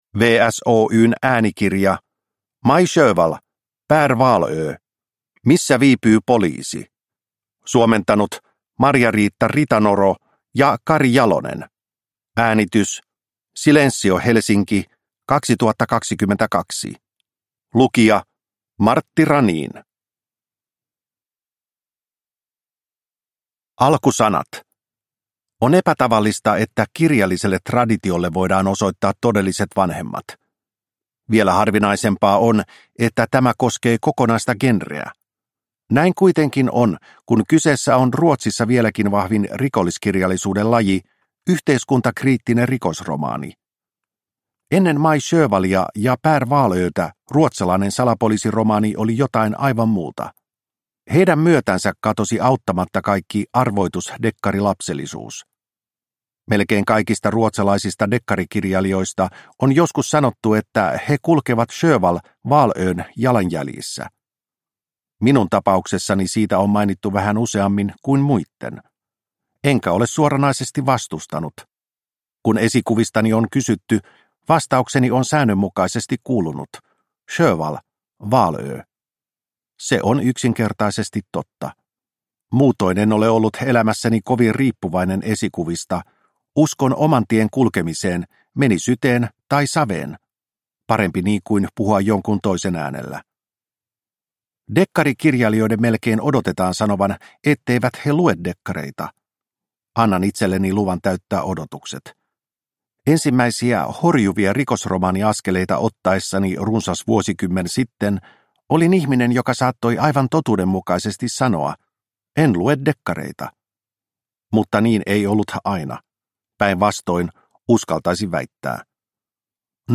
Missä viipyy poliisi – Ljudbok – Laddas ner